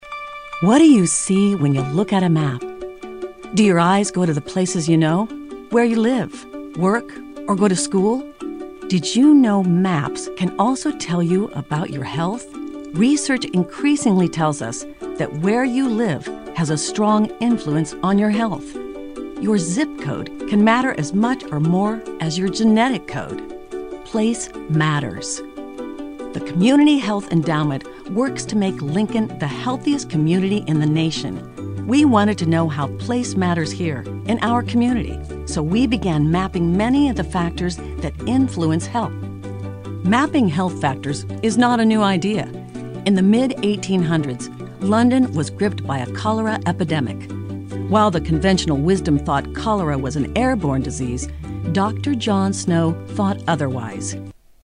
女英133 美式英语英式英语女声 广告宣传课件 沉稳|积极向上|亲切甜美